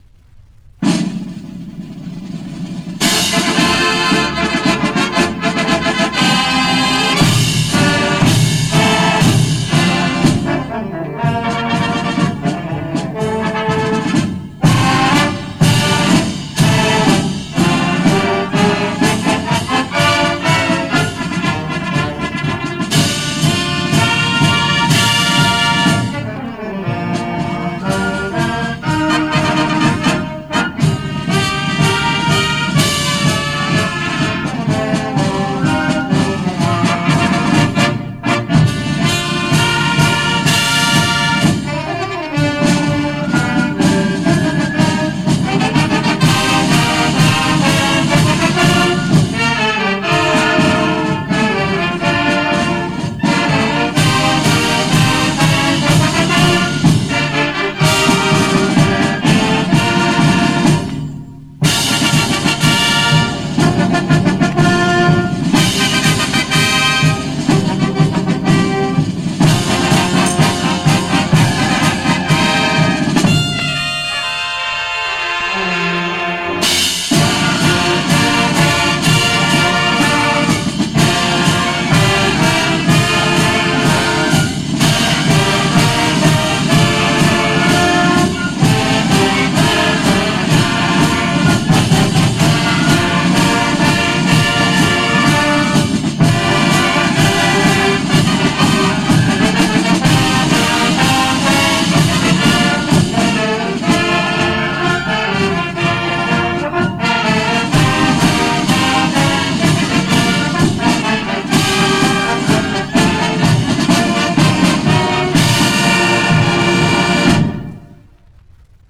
1970s – Wings of Victory March by Franke Ventre